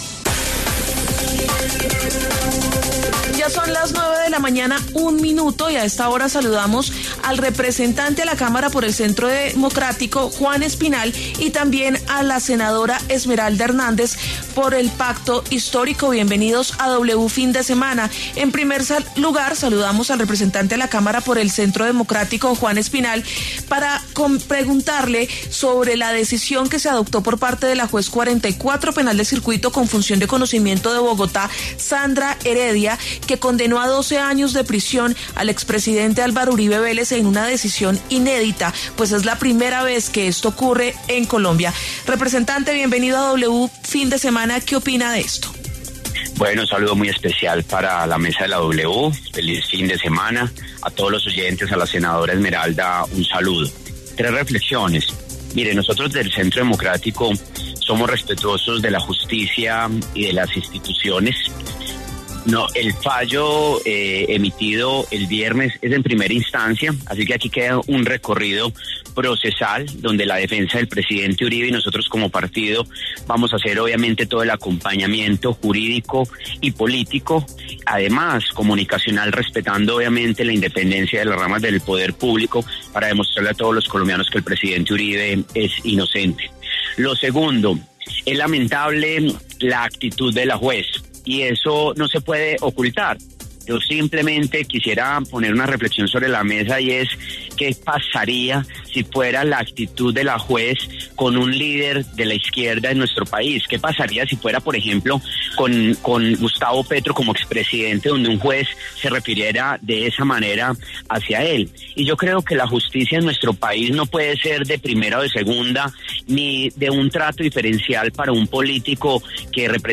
Por este motivo, el representante del Centro Democrático Juan Espinal habló en los micrófonos de W Fin De Semana para ofrecer su perspectiva al respecto.